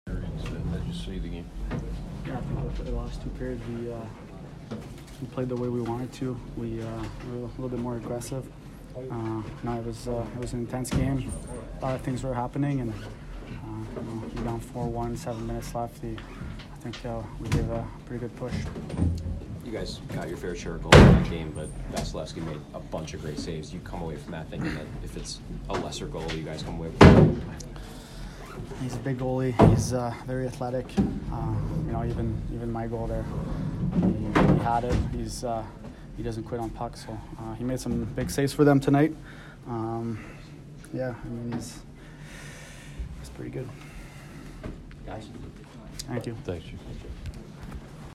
Jakub Voracek post-game 2/15